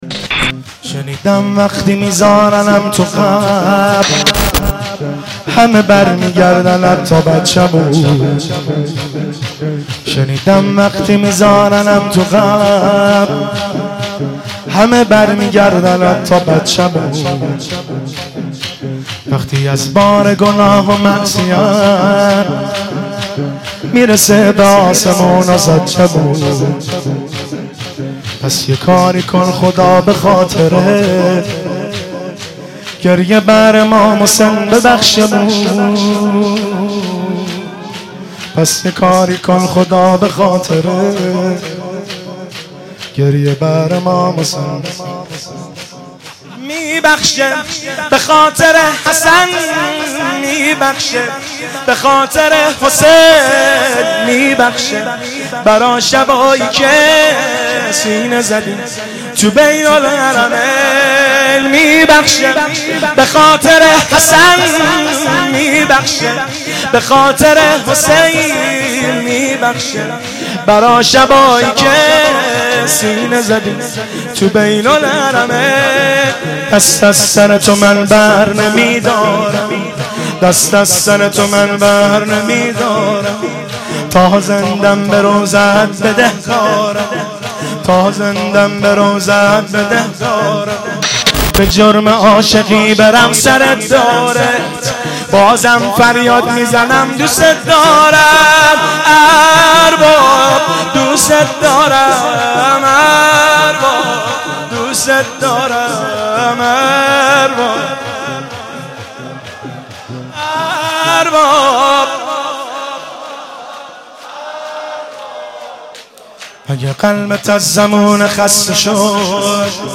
مجموعه نوحه های جلسه هفتگی
با نوای گرم
در بین الحرمین تهران اجرا شده است